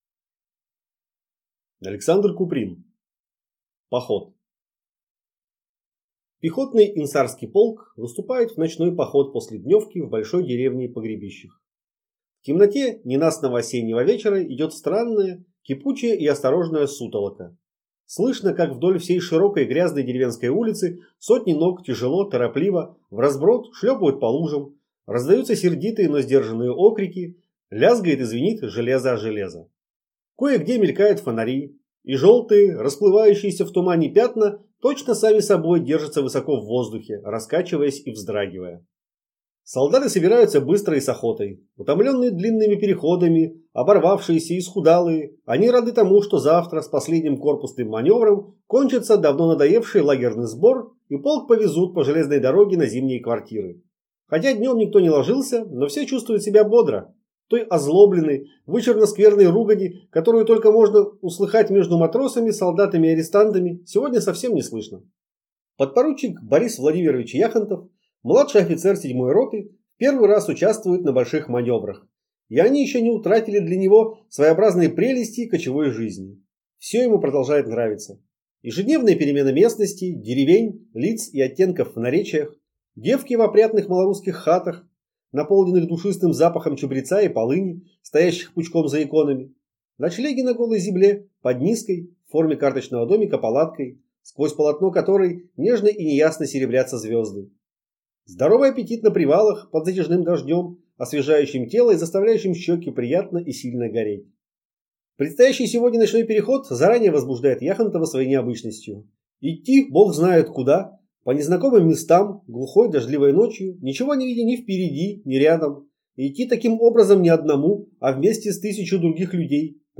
Aудиокнига Поход